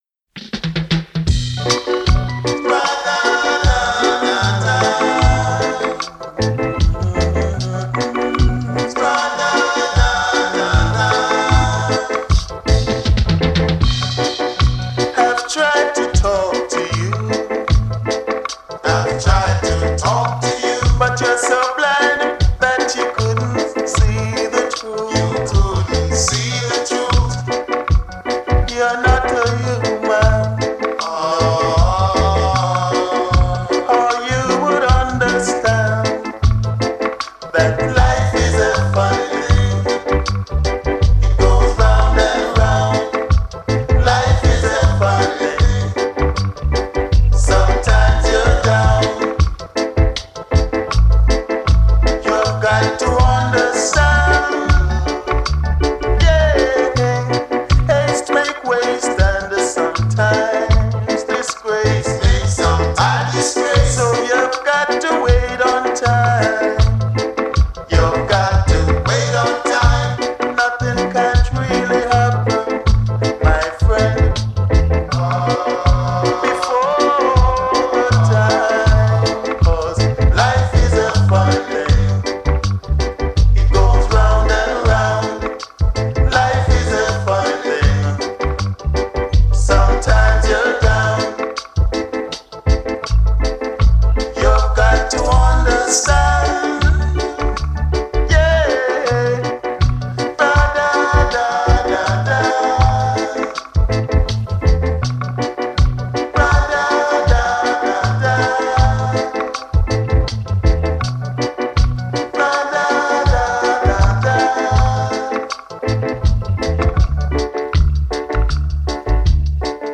KILLER piece from the early days, (1974), of the Black Ark